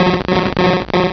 Cri de Krabby dans Pokémon Rubis et Saphir.